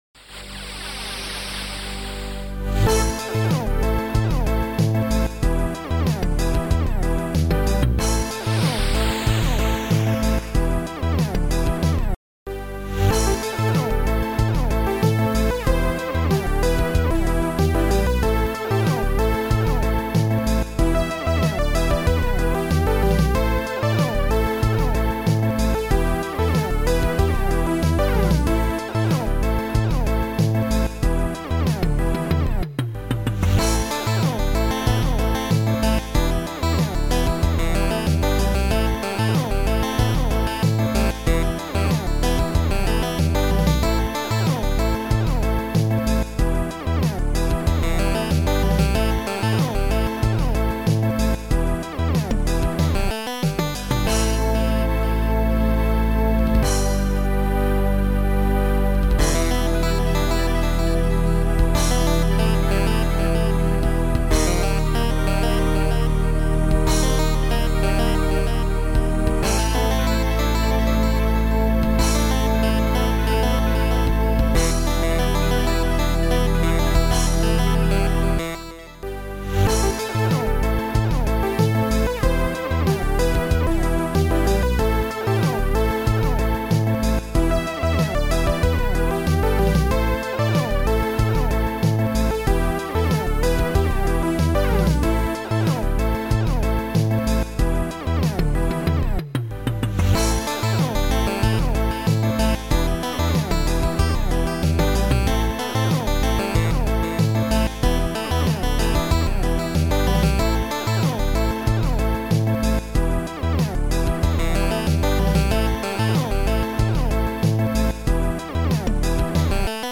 Sound Style: Mellow